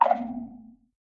Sculk Sensor sculk clicking6.ogg
Sculk_Sensor_sculk_clicking6.ogg